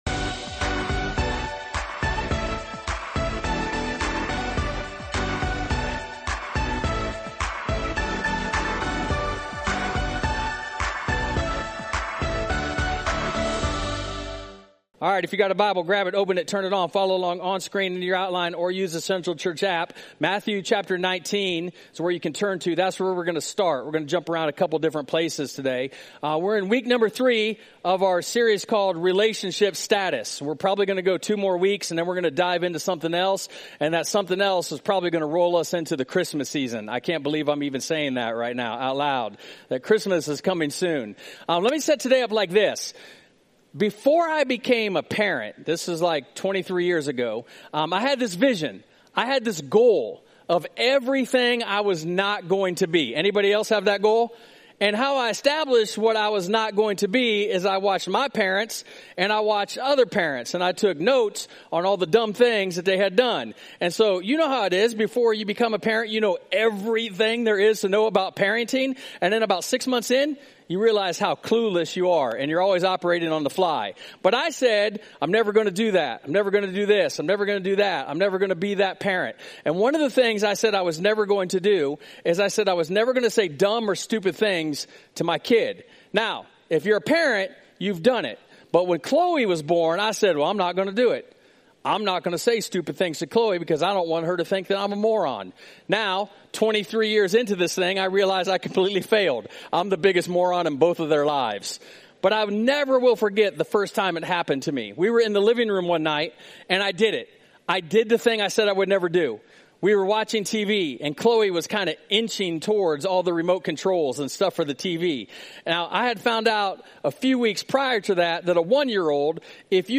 (Even if you're not affected by divorce, this message is for everyone.)